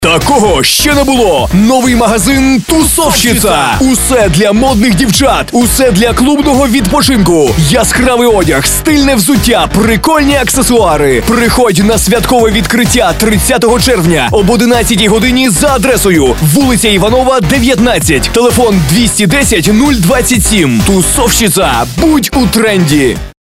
Ролик делаем мы сами, в студии.
Рекламный ролик для магазина Тусовщица